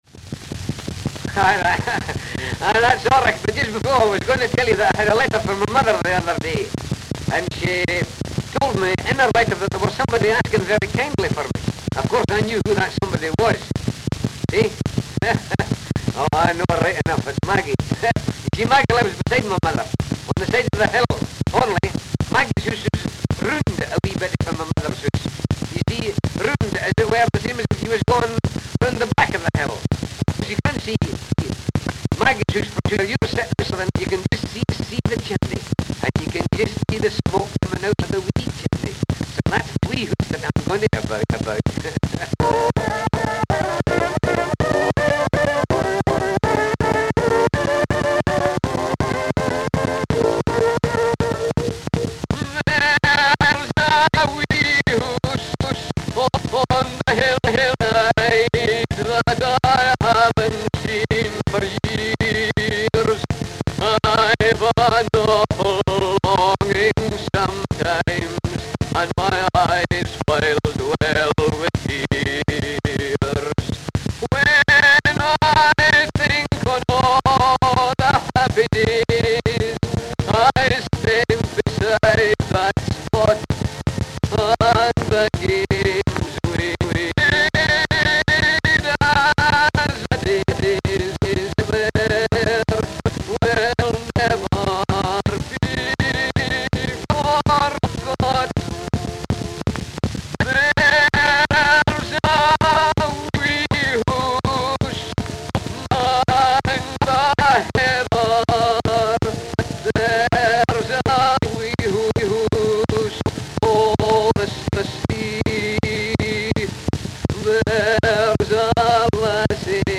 ca. October 1913 (London, England) (3/5)